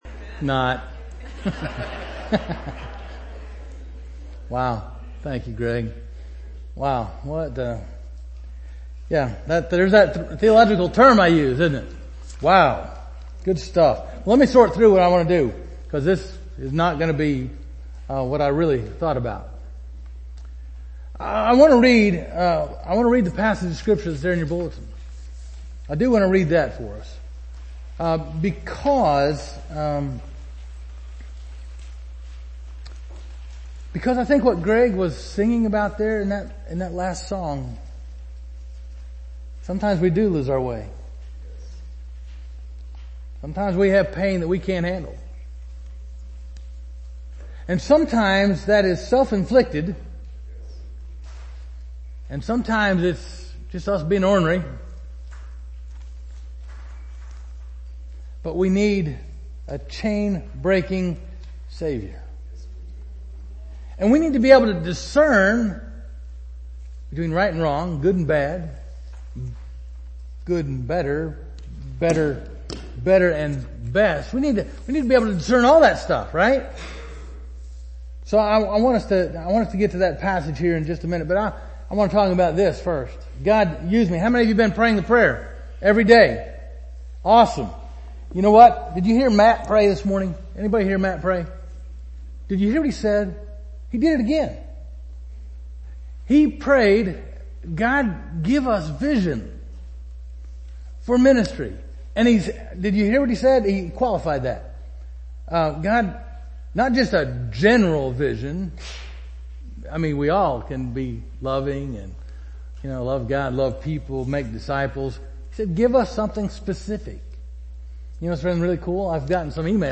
Covenant United Methodist Church Sermons